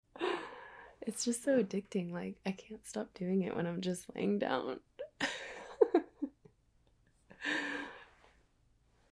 🌟 With her soft spoken words and calming ASMR triggers, you'll unwind into serenity.